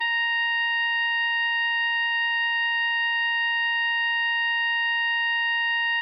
Playback sounds of Bb Clarinet having this weird glitchy sound?
I've found out the glitchy sound mostly occurs during high notes (past C5) played at louder dynamics (forte, fortissimo etc.)
Below are recordings of the C6 note in MuseScore 4 and MuseScore 3.
C6_BbClarinet_Musescore3_0.ogg